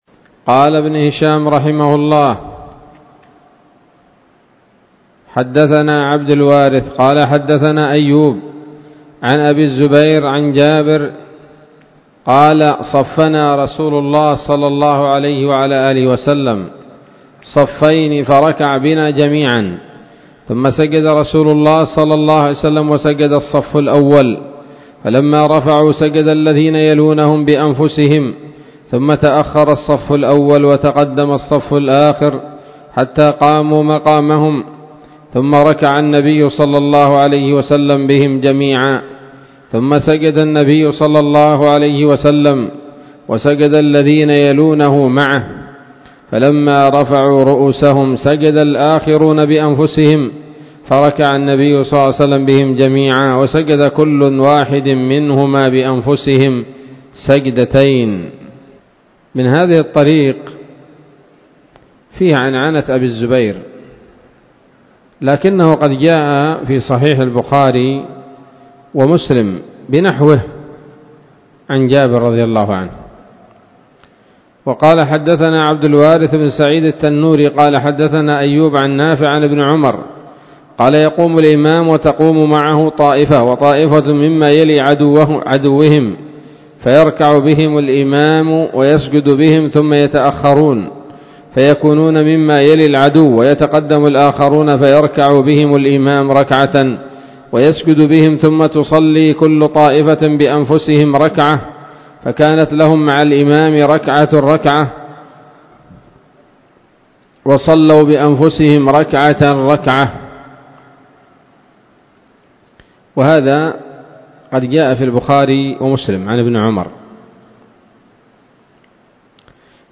الدرس الثالث والتسعون بعد المائة من التعليق على كتاب السيرة النبوية لابن هشام